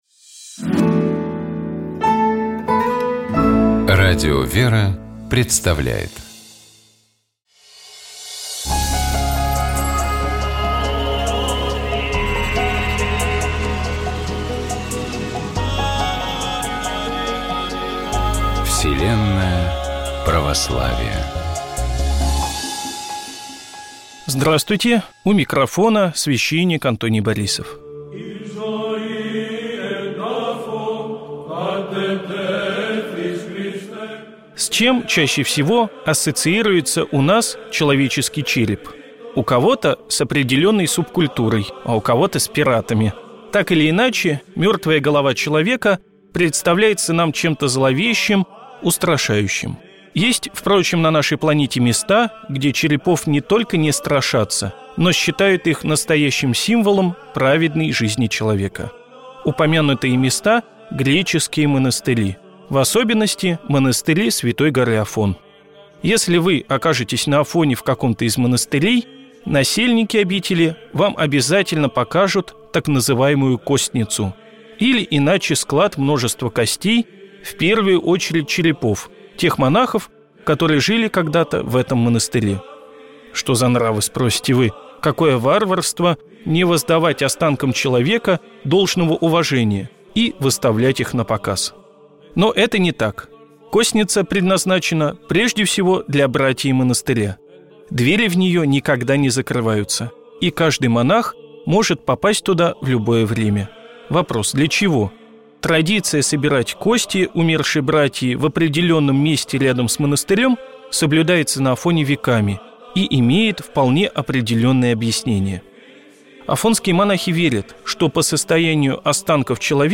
Афонские костницы: аудио-запись (эфир радио «Вера»)
Вселенная-Православия-Афонские-костницы-эфир-радио-Вера.mp3